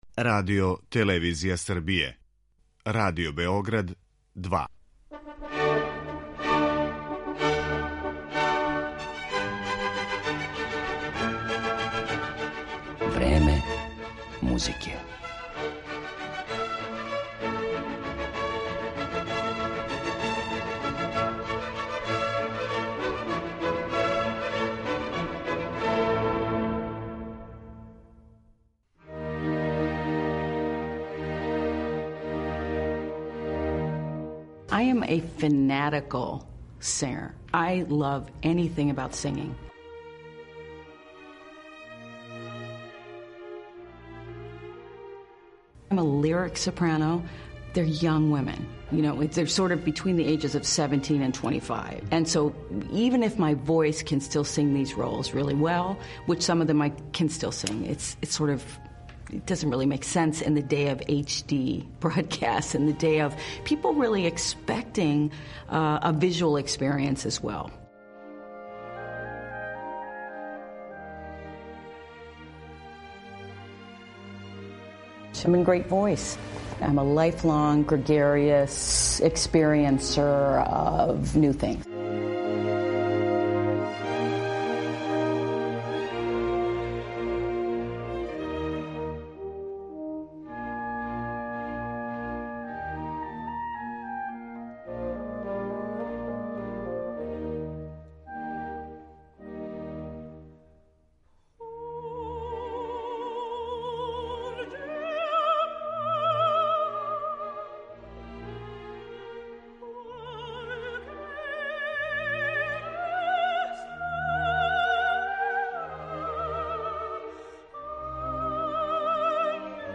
Део тог репертоара чућете у данашњој емисији